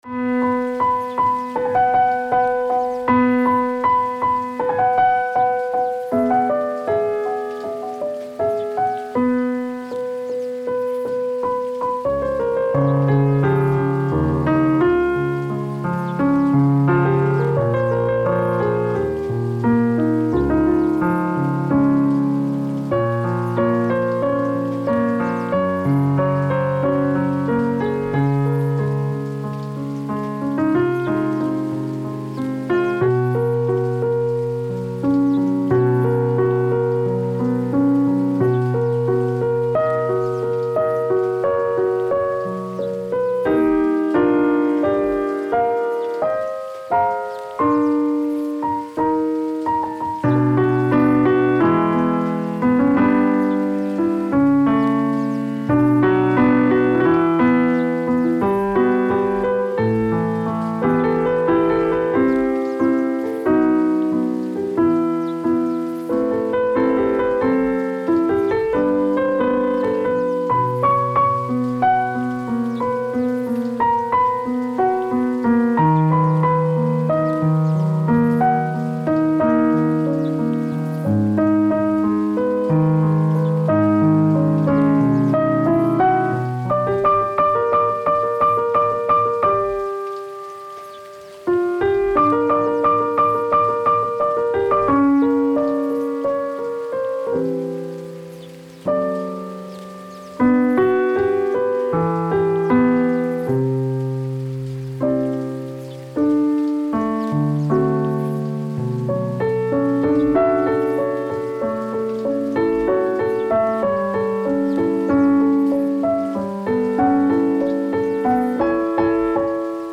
Genre:Cinematic
デモサウンドはコチラ↓